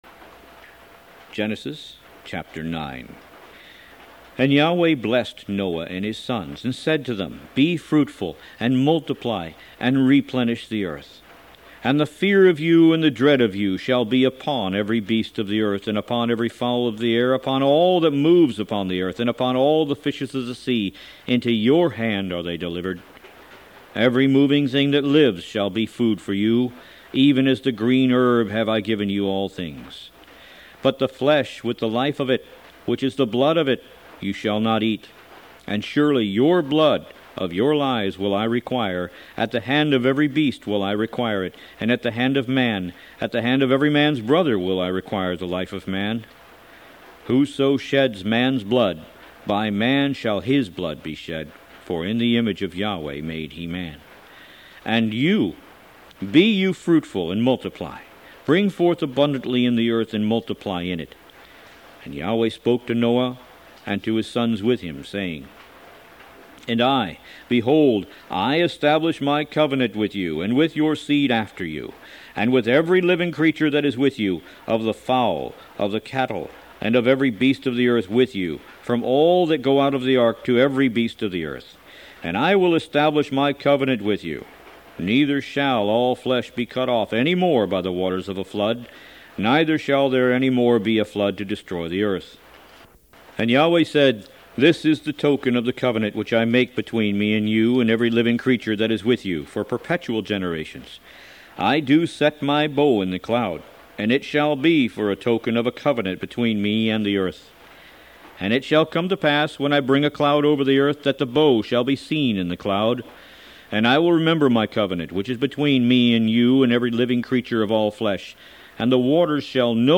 Root > BOOKS > Biblical (Books) > Audio Bibles > Tanakh - Jewish Bible - Audiobook > 01 Genesis